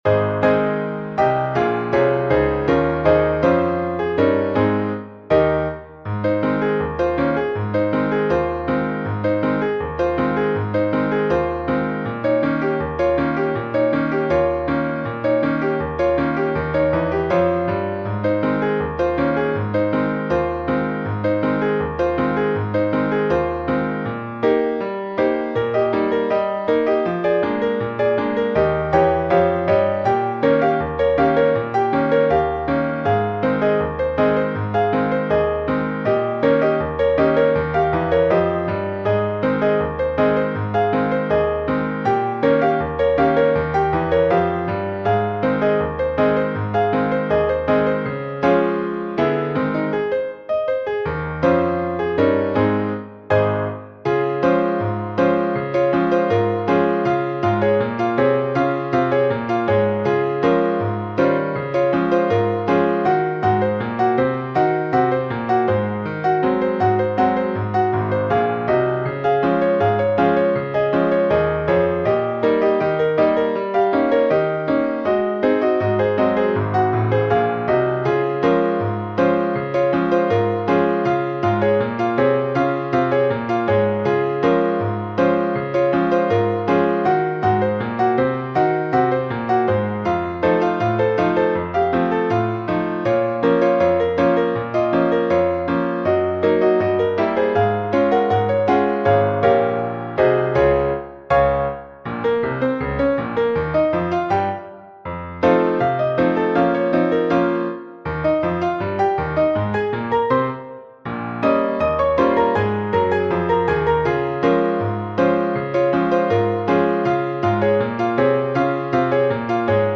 Рэгтаймы